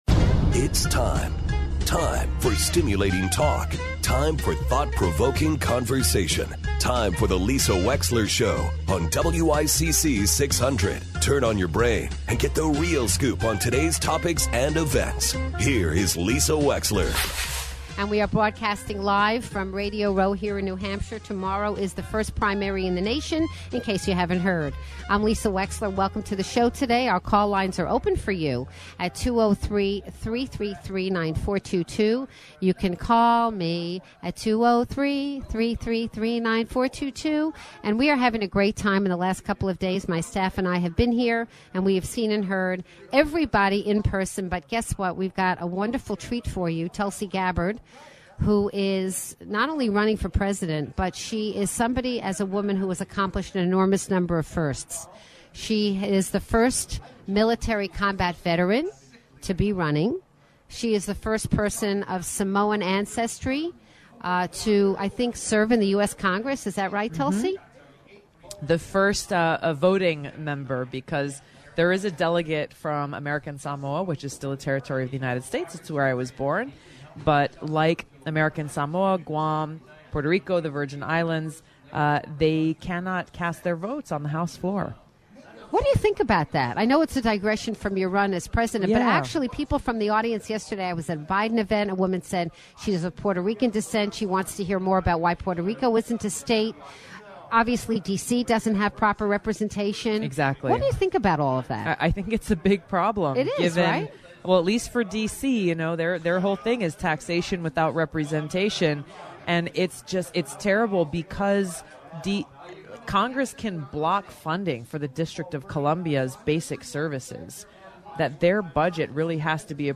Tulsi Gabbard LIVE From Radio Row